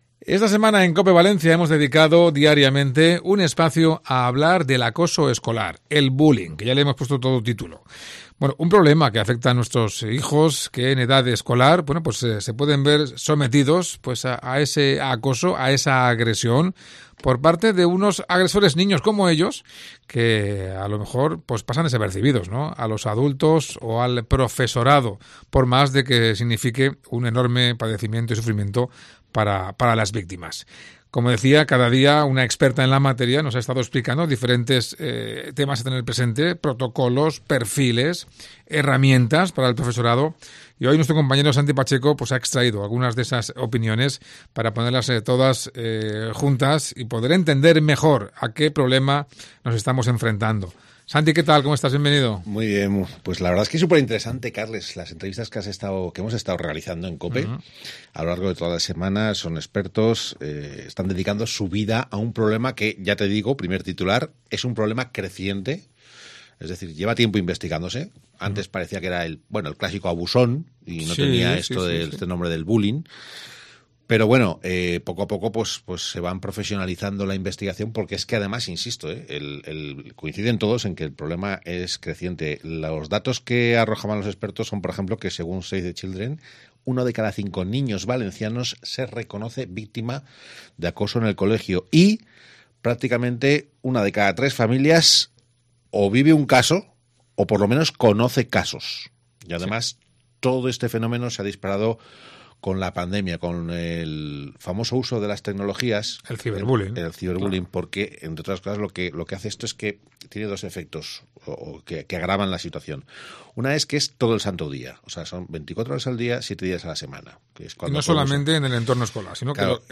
RESUMEN DE LAS REFLEXIONES DEL PANEL DE EXPERTOS SOBRE ACOSO ESCOLAR